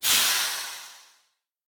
train-breaks-5.ogg